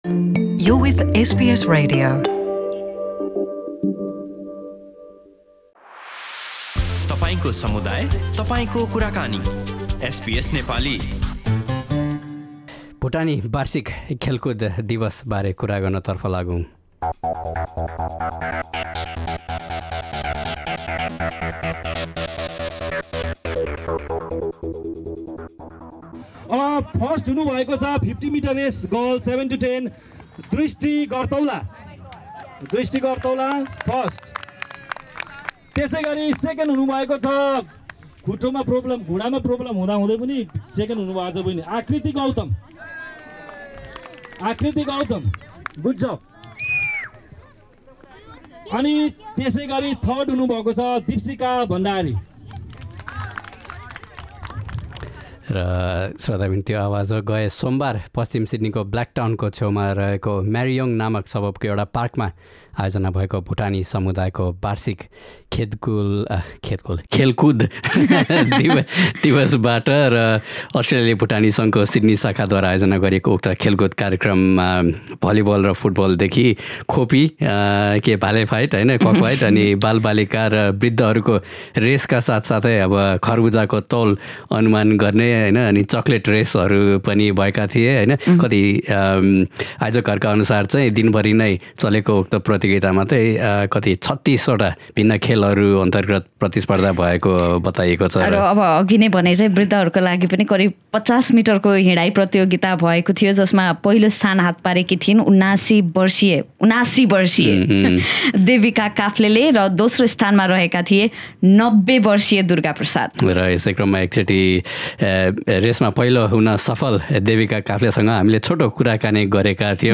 From 50-metre elderly walking races to volleyball matches to guessing a watermelon’s weight, the Australian Bhutanese community’s sports days are fun and harmonious events. Listen to what some of the participants and the organisers have to say about this engaging community event in Sydney.